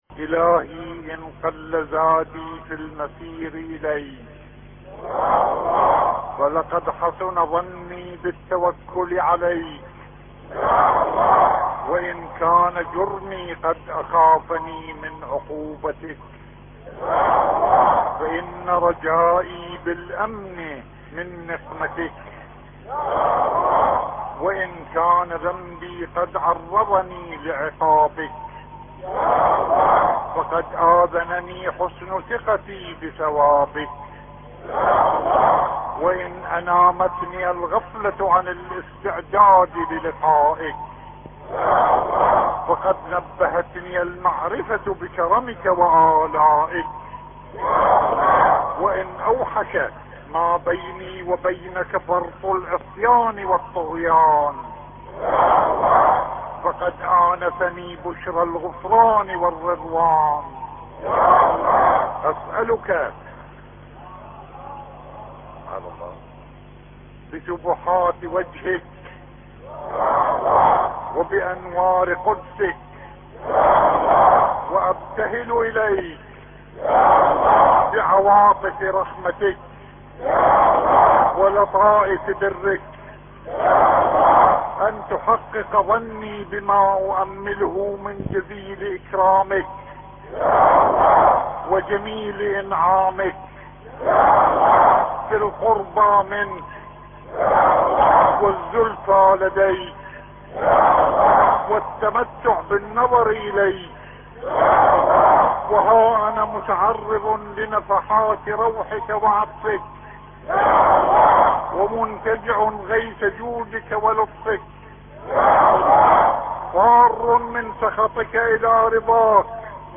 مناجاة الراغبين بصوت السيد الشهيد محمد الصدر قدس سره مقتطع من احدى خطب الجمعة المباركة التي القاها سماحته في مسجد الكوفة المعظم .